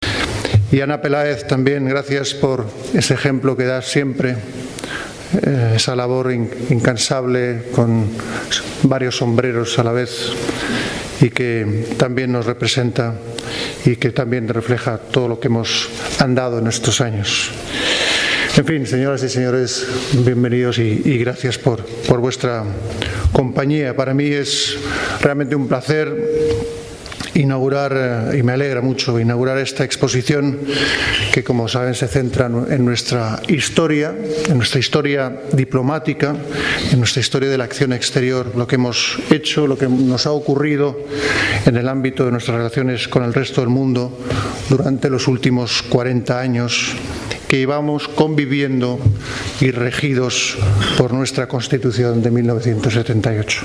Por su parte, el rey Felipe VI, cerró el acto, con el que inauguró la exposición, manifestando su satisfacción por las palabras del ministro Borrell, de Vargas Llosa y de la representante española en la ONU, Ana Peláez, a quien